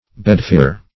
Search Result for " bedfere" : The Collaborative International Dictionary of English v.0.48: Bedfere \Bed"fere`\ Bedphere \Bed"phere`\, n. [Bed + AS. fera a companion.]